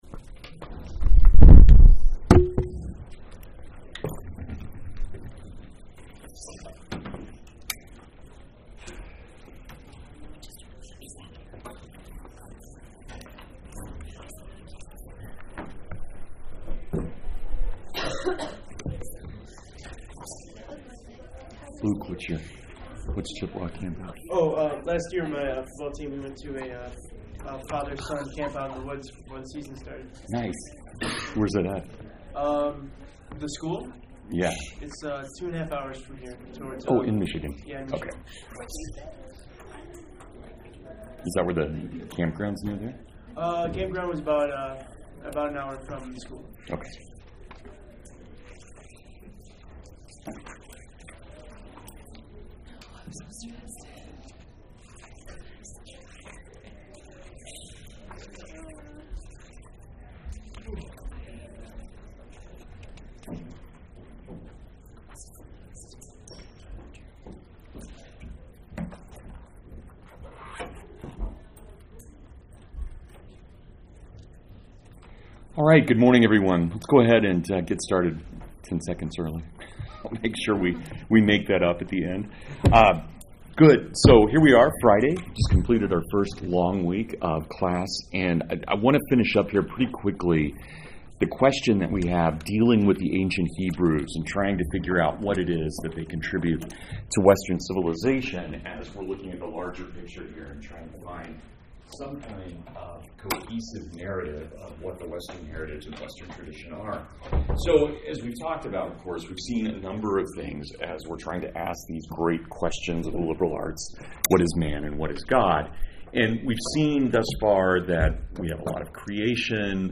Western Heritage Lecture 5: No God Kings